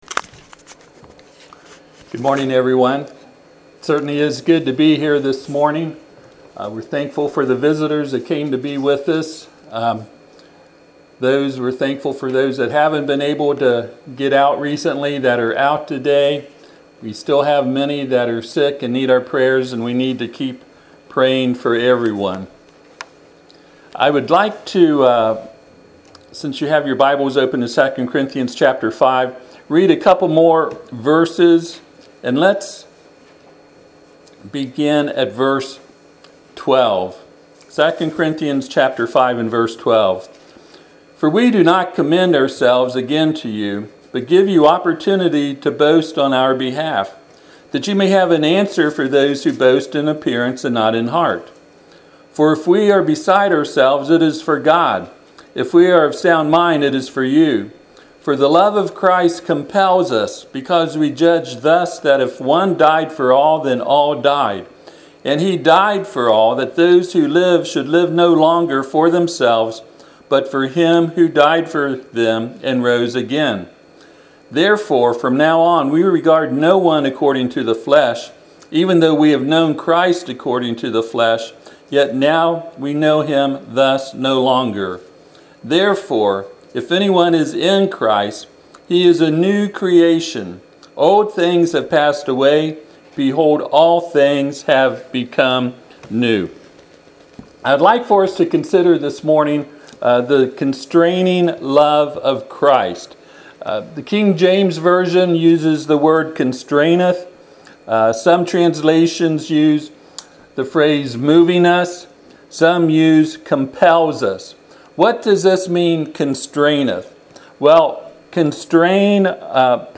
Passage: 2 Corinthians 5:12-17 Service Type: Sunday AM Topics